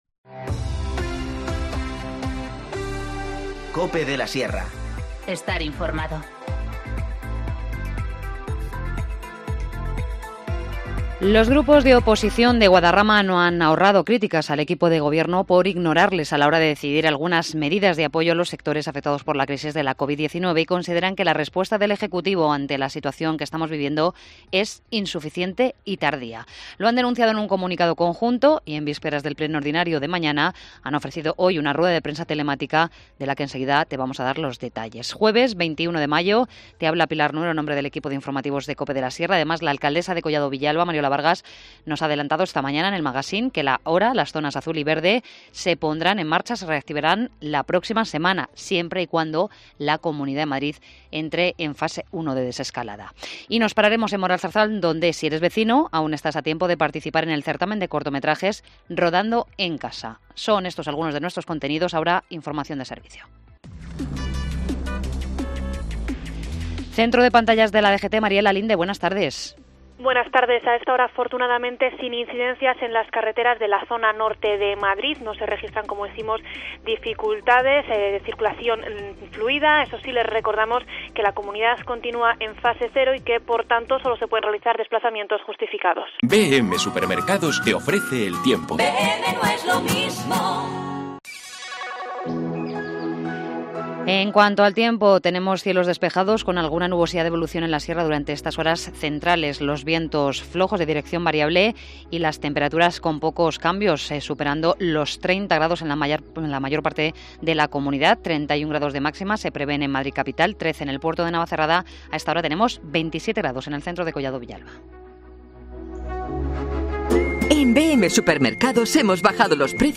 Informativo Mediodía 21 mayo 14:20h